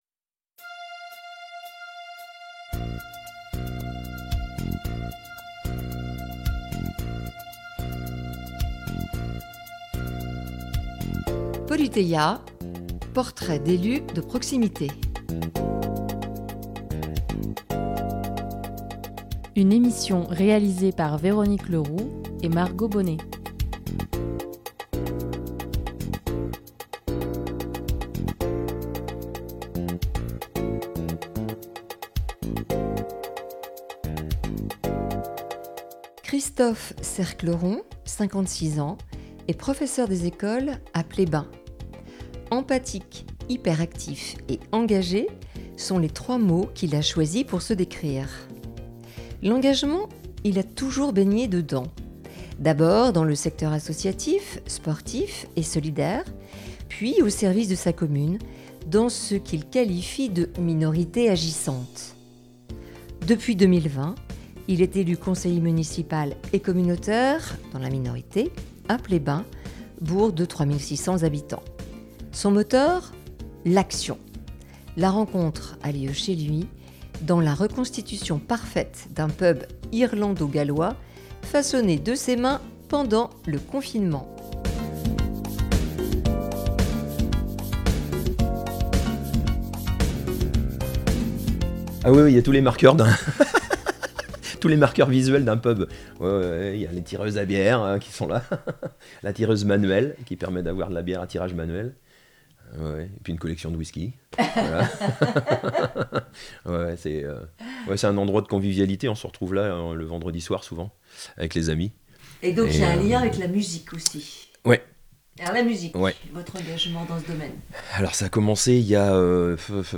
L'entretien a lieu, chez lui, en mai 2024, dans la reconstitution parfaite d’un « Pub » irlando-gallois façonné de ses mains pendant le confinement.